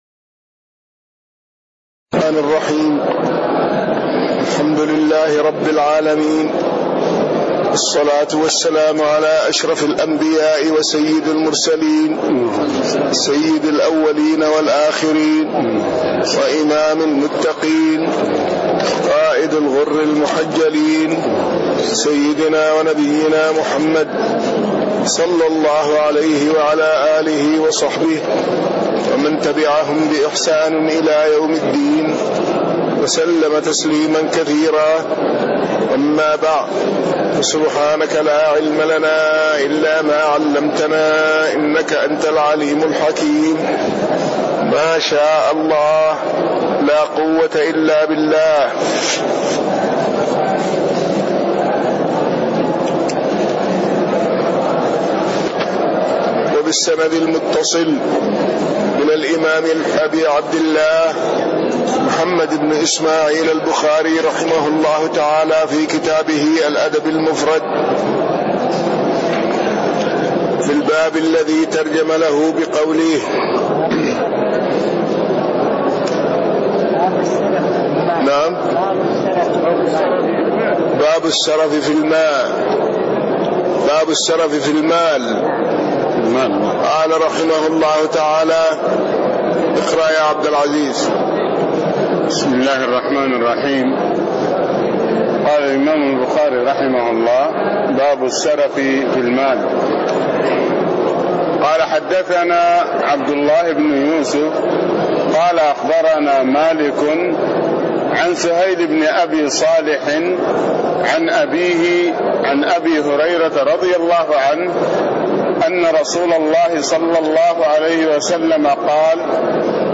تاريخ النشر ٣ جمادى الآخرة ١٤٣٤ هـ المكان: المسجد النبوي الشيخ